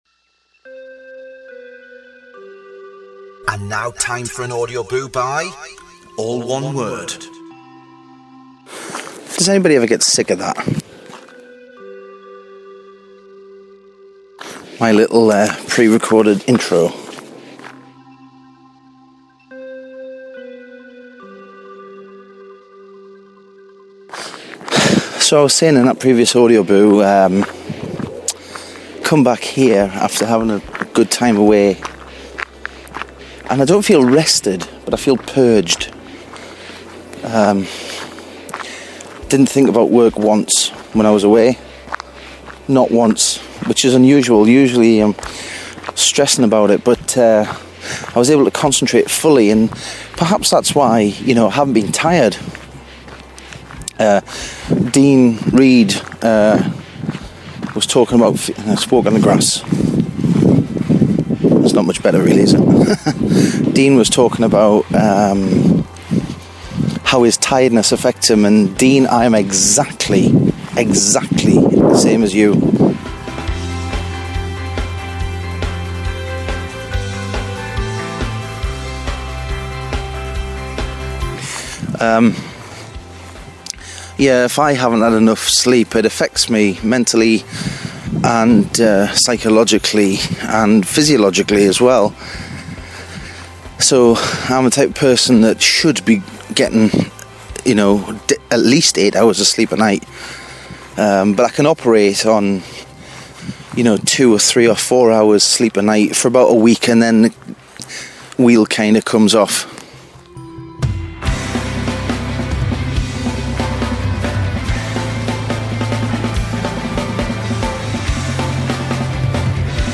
This Audioboo didn't export properly, but when I went back into Bossjock, the ending of the original version was also missing!